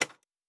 pgs/Assets/Audio/Fantasy Interface Sounds/UI Tight 24.wav
UI Tight 24.wav